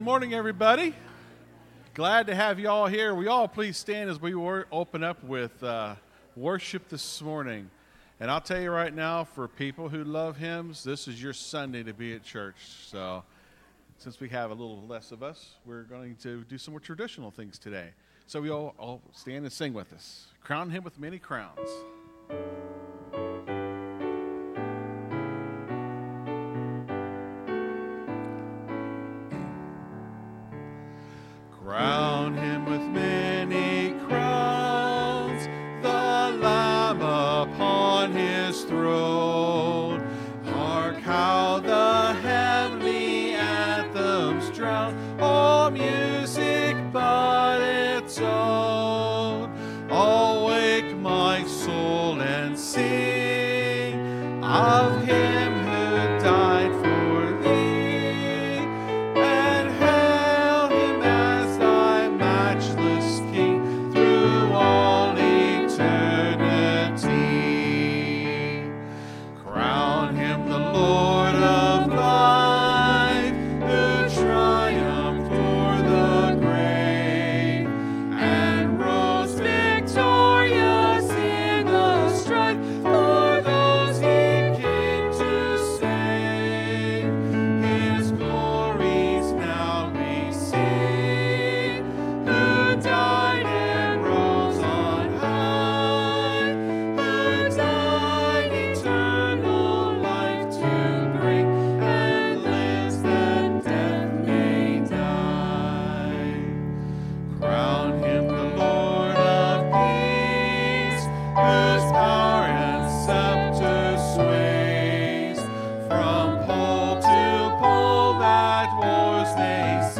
(Sermon starts at 27:20 in the recording).